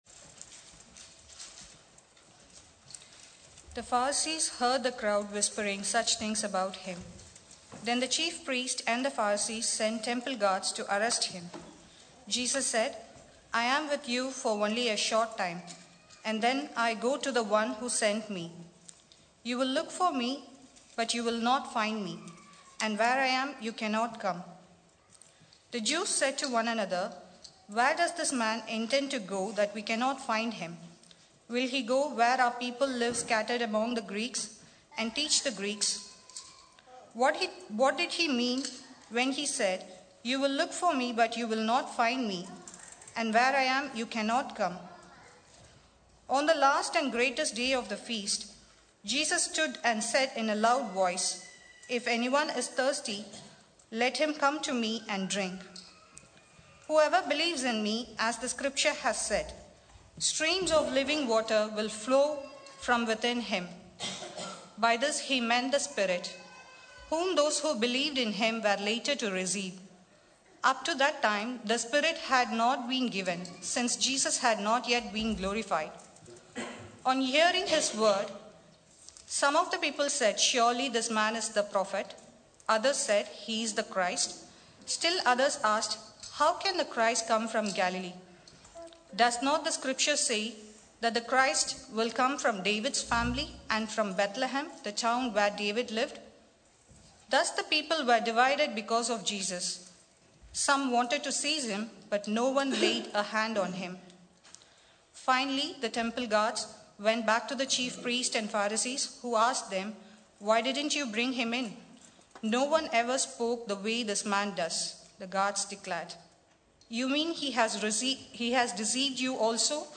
Emmanuel Church Chippenham | Sermons